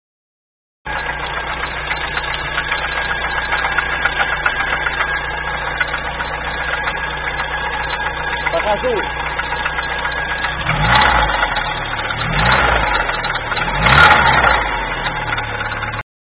Все так серьезно ?Я только что заводил - звук такой удары (тук тук тишена . . . . . . тук тишена................тук тук ) Точнее не тук а цик ) Вот такую песню поет
А вот что я еще заметил на холодную звук еле слышен на горячую слышен нормально и звук меняется при нажатии на газ .